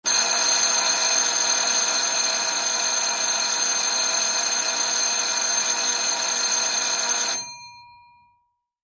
Office Bell Sound Effect Free Download
Office Bell